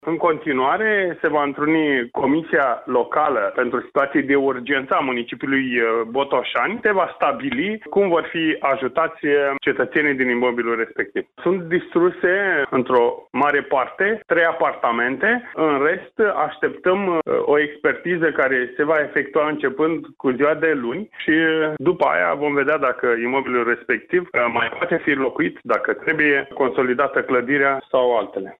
O echipă de specialiști de la Inspectoratul de Stat în Construcții Botoşani evaluează clădirea în care a avut loc deflagraţia pentru a stabili dacă imobilul mai poate fi locuit, a mai spus prefectul Dan Şlincu: